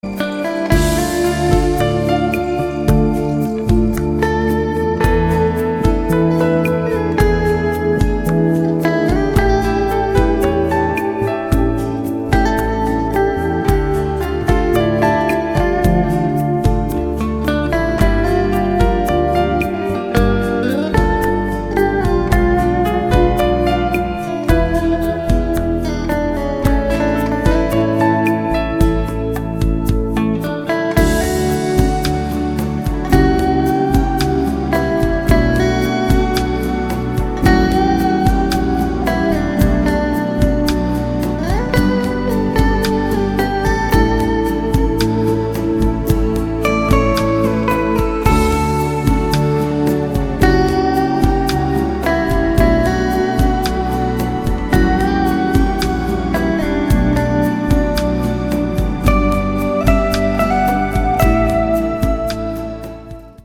• Качество: 192, Stereo
гитара
спокойные
без слов
инструментальные
медленные
романтичные